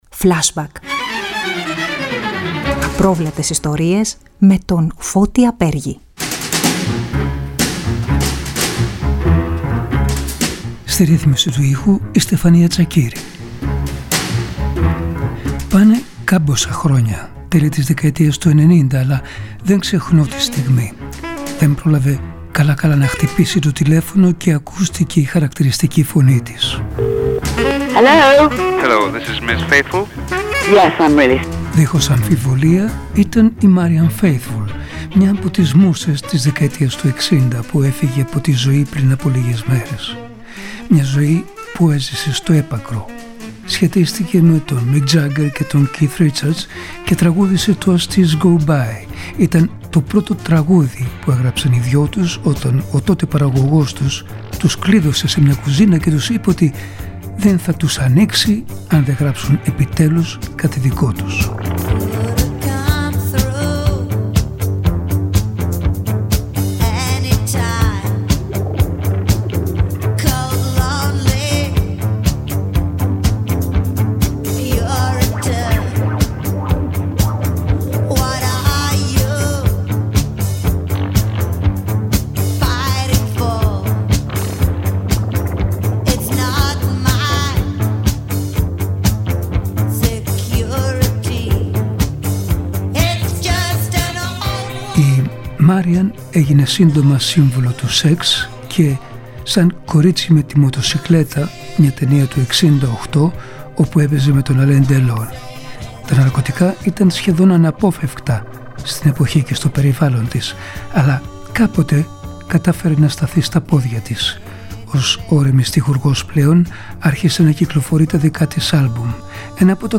Η Marianne Faithfull σε μια συνέντευξη- ντοκουμέντο